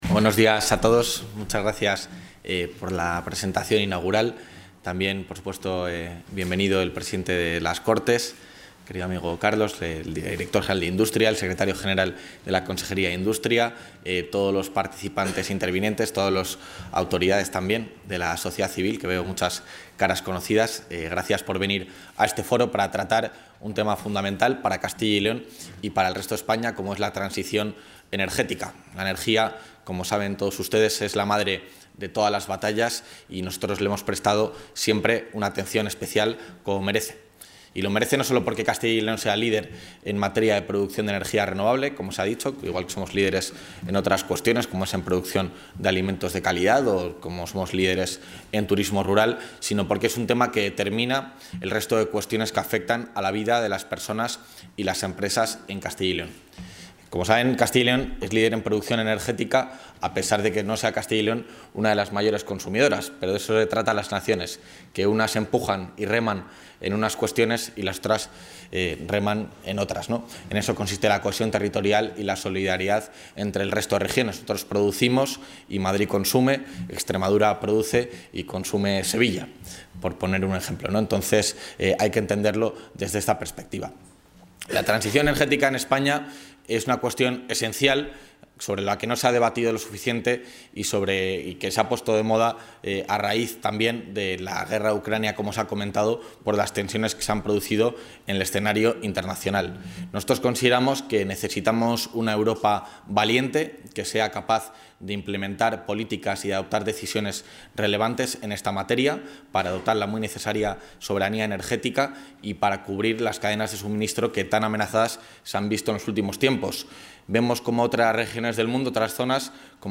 Intervención del vicepresidente de la Junta.
El vicepresidente de la Junta de Castilla y León, Juan García-Gallardo, ha inaugurado hoy en el Salón de actos de la Cámara de Comercio e Industria de Valladolid el foro ‘El liderazgo de Castilla y León en la transición energética’, organizado por EL ESPAÑOL-Noticias de Castilla y León.